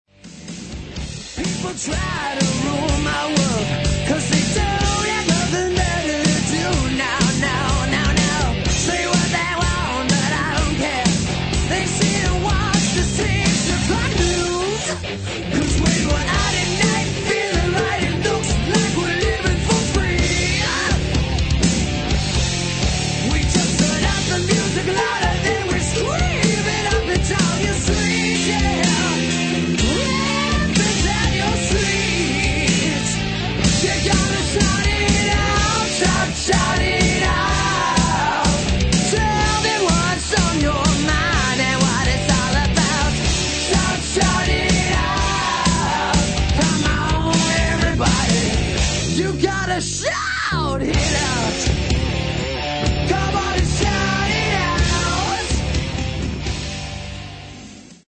Recorded at: Pasha Music House, Hollywood, CA